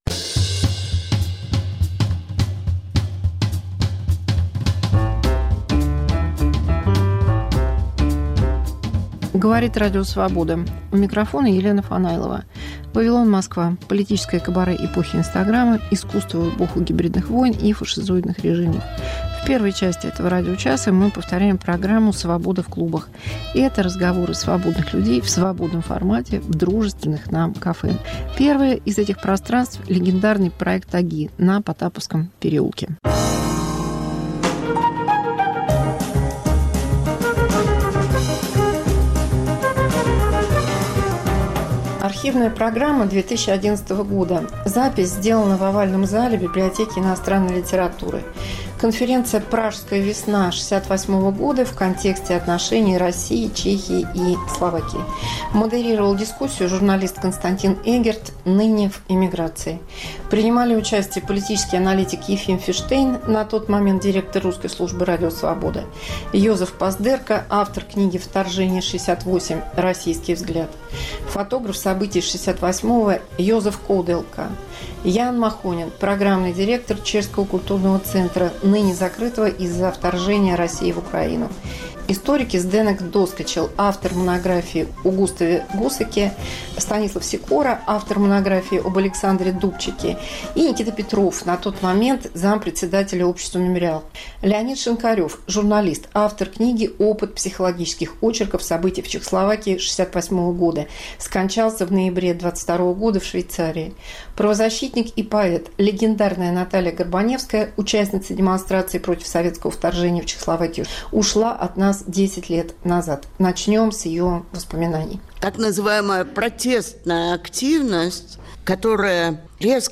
Чтение с комментариями